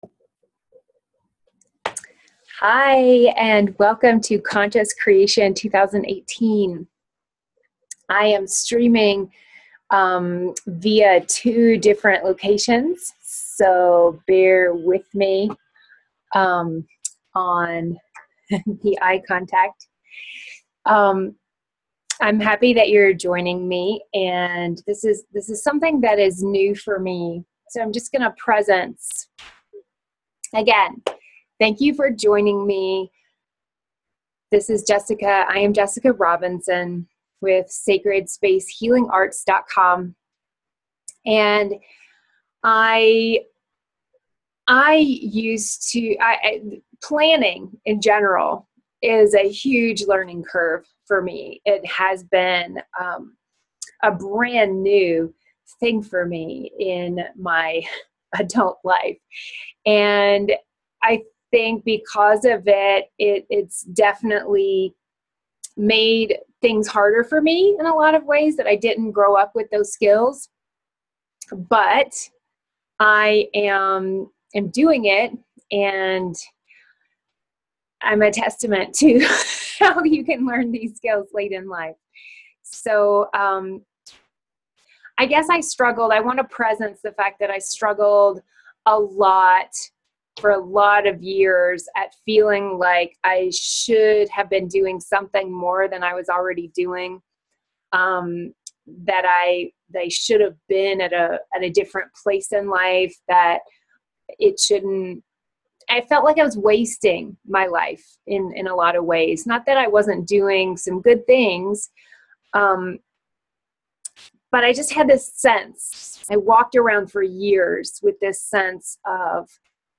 Conscious Creation 2018 – Live Workshop